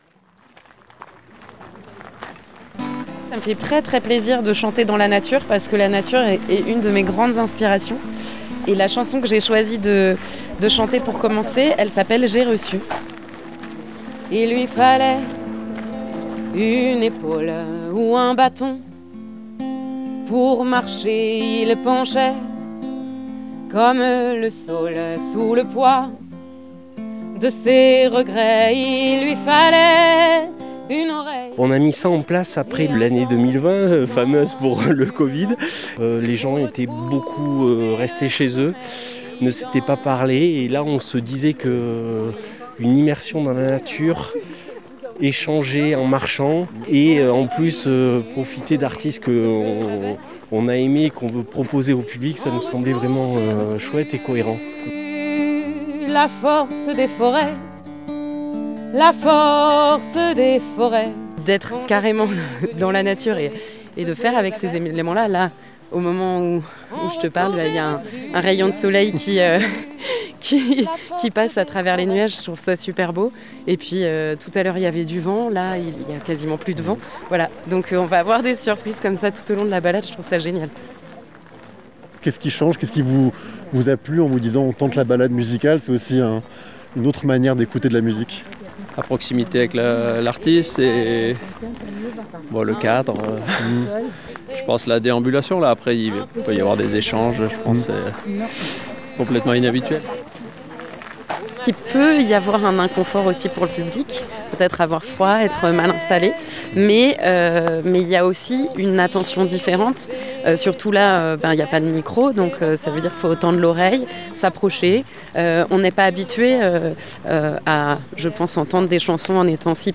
A découvrir : impressions du public, mots et chansons de l’artiste, et le regard des organisateurs…